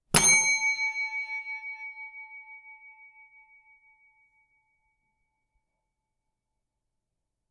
ding 2
bell desk hotel service sound effect free sound royalty free Sound Effects